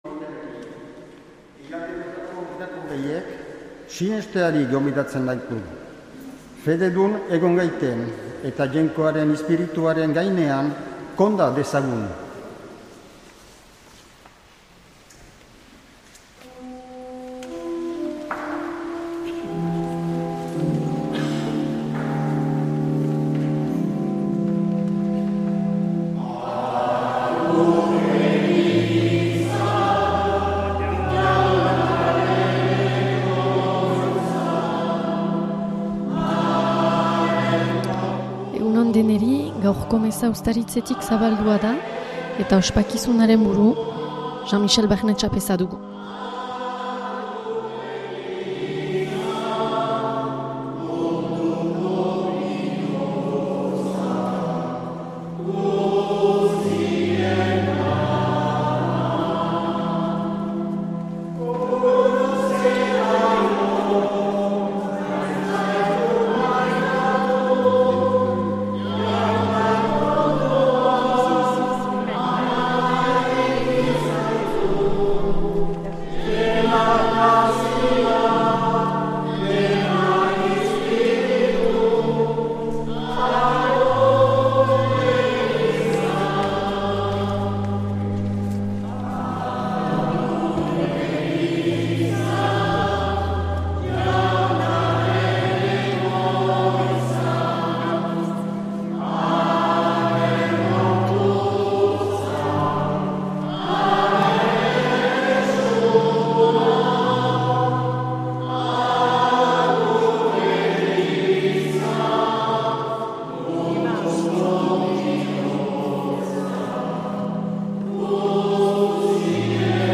Accueil \ Emissions \ Vie de l’Eglise \ Célébrer \ Igandetako Mezak Euskal irratietan \ 2025-10-05 Urteko 27.